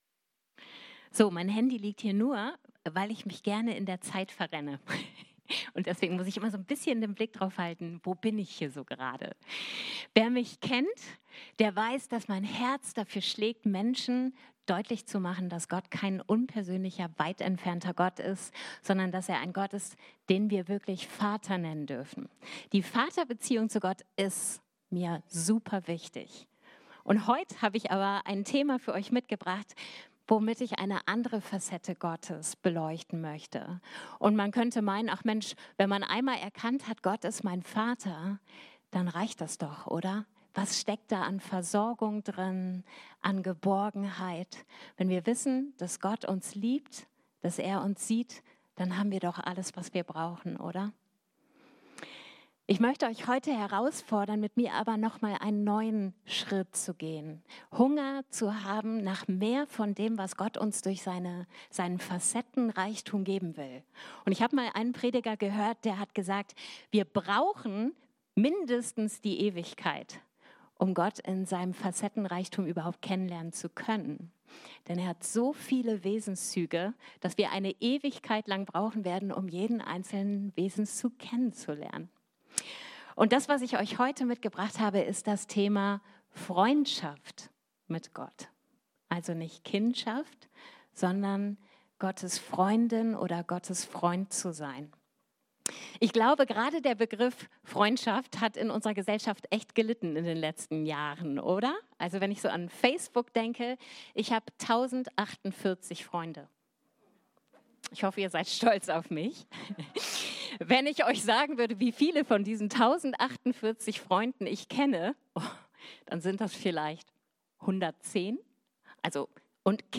Predigt: „Freundschaft mit Gott“